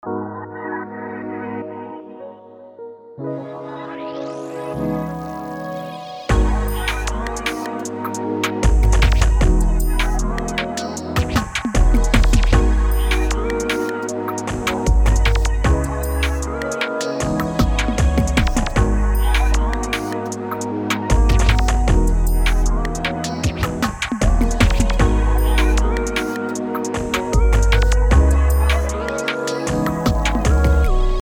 BPM: 154
Key: Ab Minor
Preview del beat: